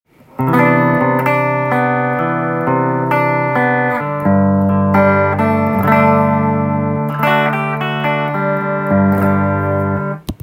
ロックで渋いエレキギター【MUSICMAN Axis】
このギターを試しに弾いてみました
ハムバッカーのピックアップなのでクリーントーンが非常に太く
中音域が強調されています。